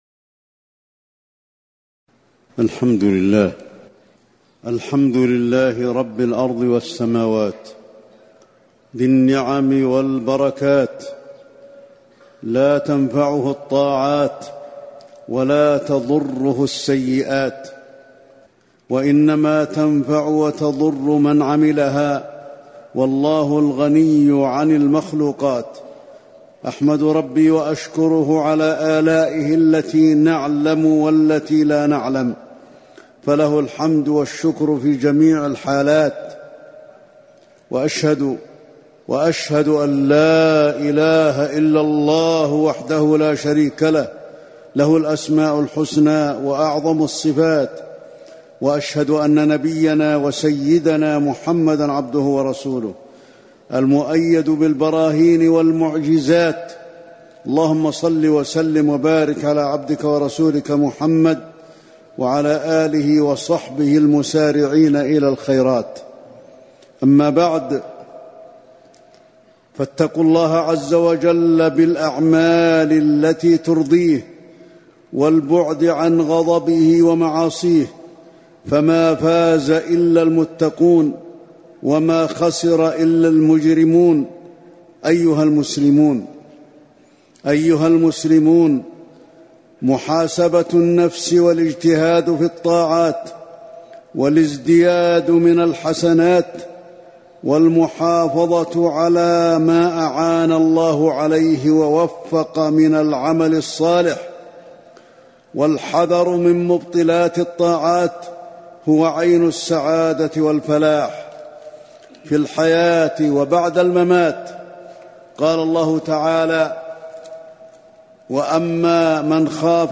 تاريخ النشر ٥ رمضان ١٤٤٠ هـ المكان: المسجد النبوي الشيخ: فضيلة الشيخ د. علي بن عبدالرحمن الحذيفي فضيلة الشيخ د. علي بن عبدالرحمن الحذيفي محاسبة النفس والإجتهاد بالطاعات